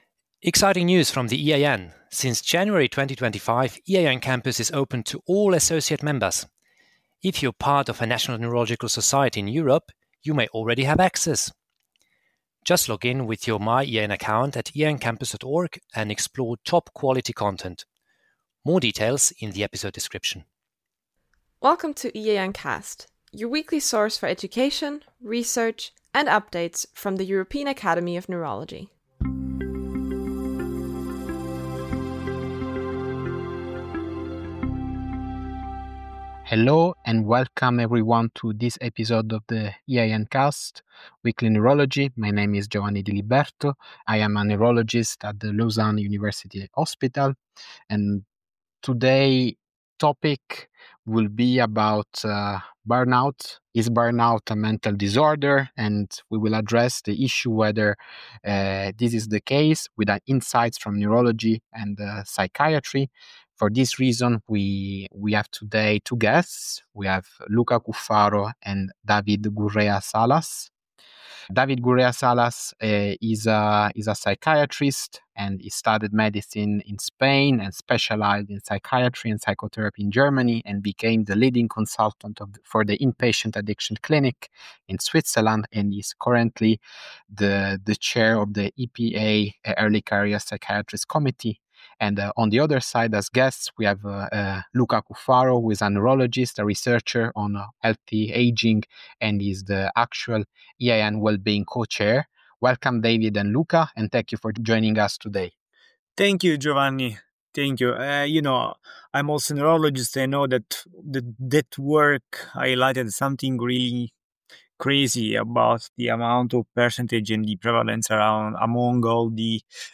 Together, they unpack the neurological and psychological dimensions of burnout, its clinical recognition, and what it means for diagnosis and treatment. Tune in for a nuanced discussion bridging science, mental health, and real-world implications.